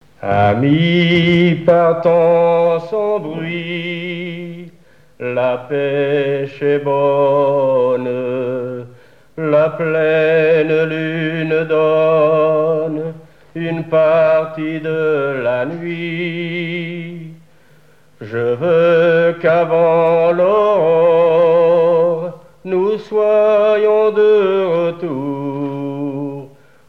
chansons dont de marins
Pièce musicale inédite